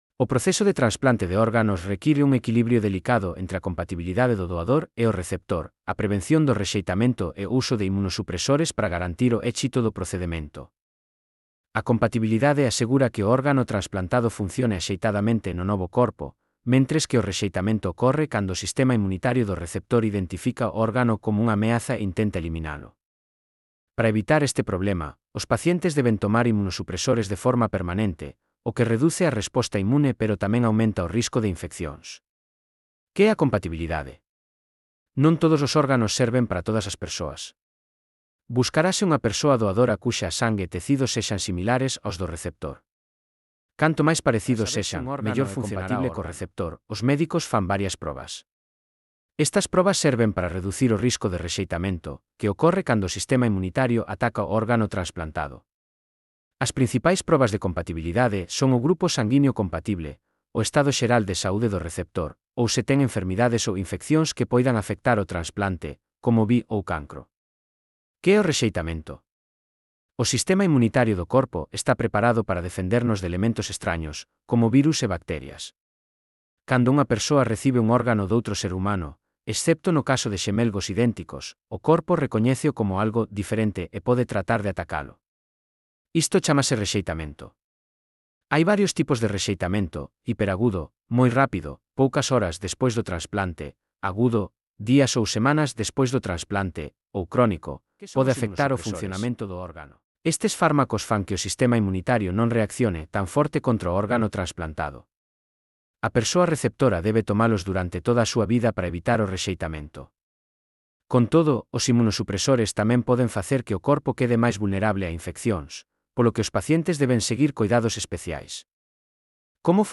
Elaboración propia coa ferramenta Narakeet.